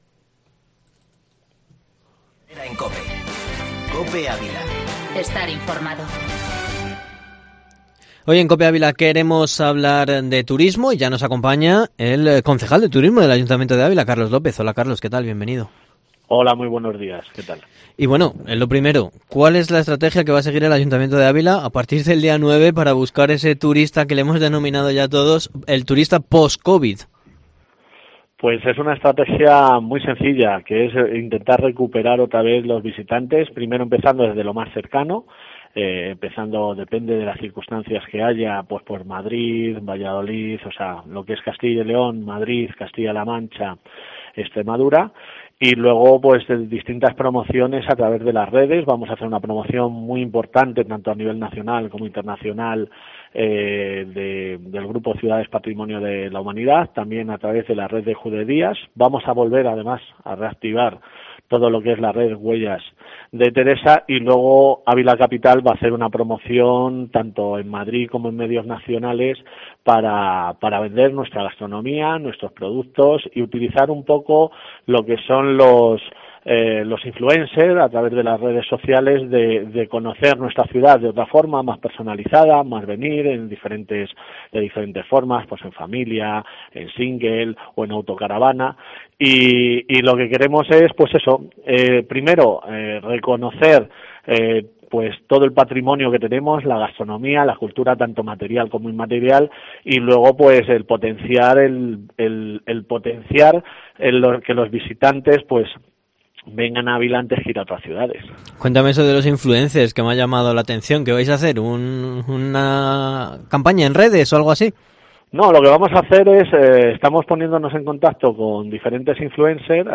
Entrevista con el concejal de turismo Carlos Lopez en COPE